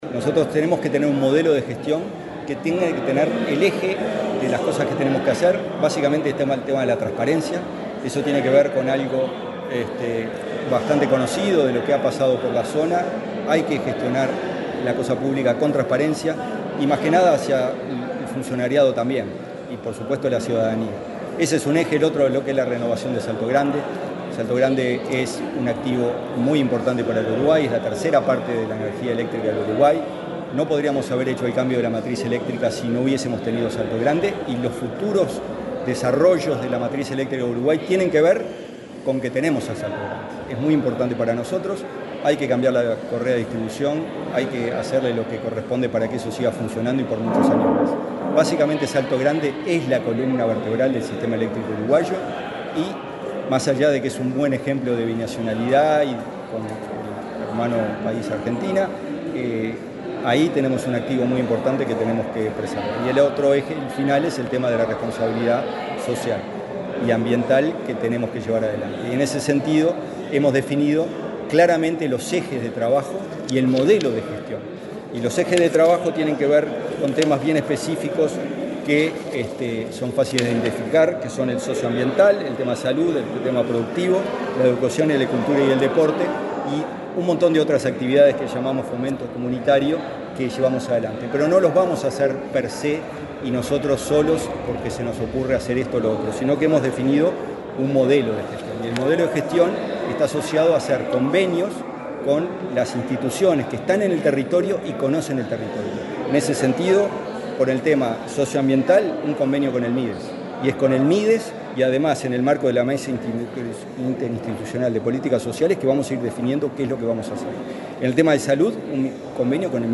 Declaraciones del presidente de la delegación uruguaya en la CTM de Salto Grande